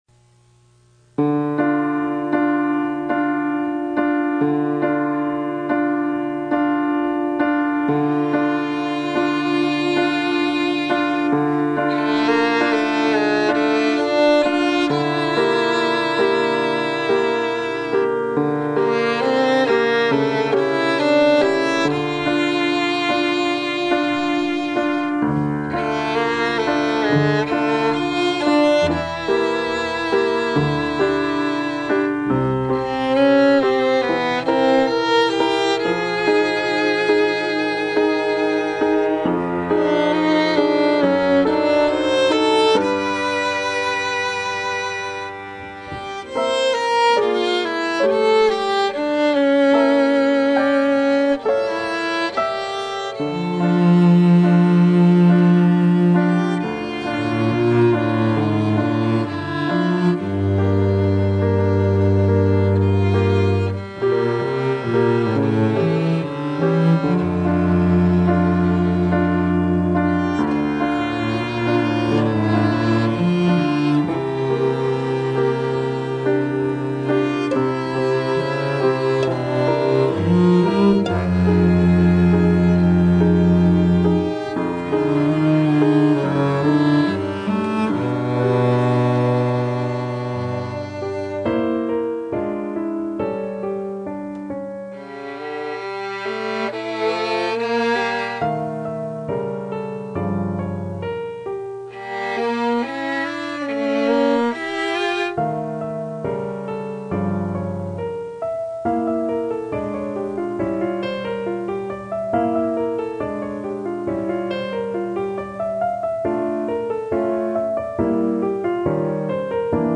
nhạc hòa tấu
sáng tác năm 2009 cho piano và nhóm tứ tấu đàn dây (2 violins, viola, và cello), do chính tác giả và các bạn nhạc sĩ trình bày và thu âm tại nhà riêng.
Cả 4 bản nhạc đều rất tinh tế về chuyển động hoà âm và cấu trúc đa điệu.